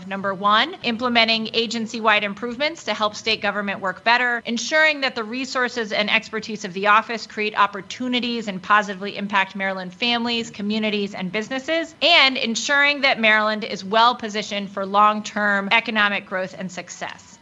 In a Tuesday press conference, Lierman said these improvements are designed to keep more money in taxpayer pockets…